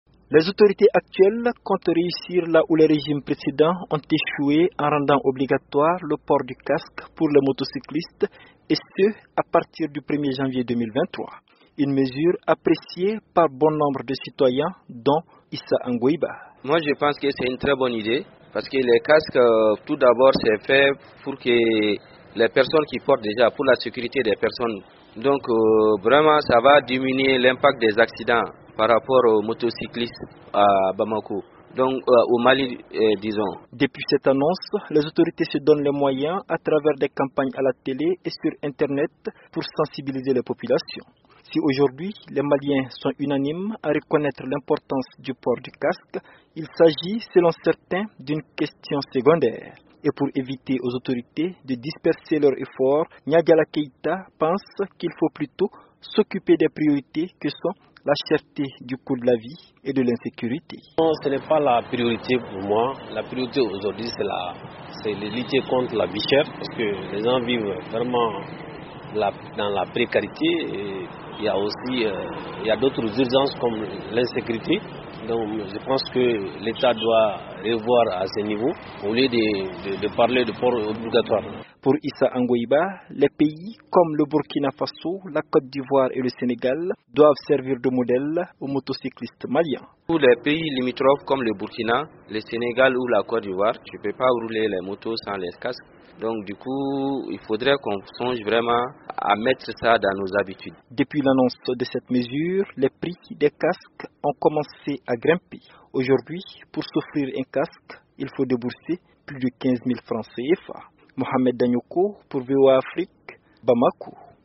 Une situation qui crée des divergences au sein d’une population où ils sont nombreux à penser que les priorités sont ailleurs. Reportage de notre correspondant,